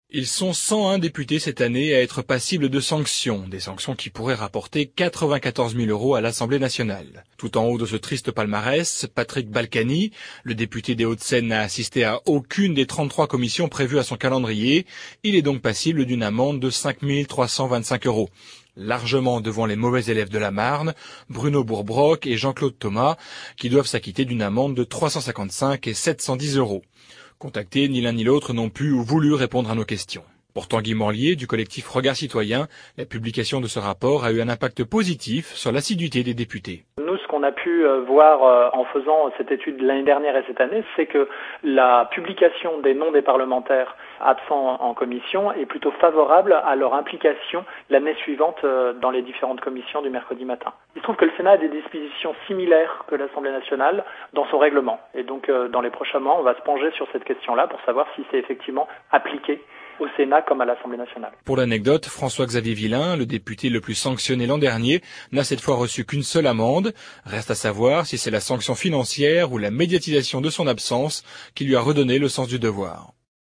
à l’antenne de France Bleu Champagne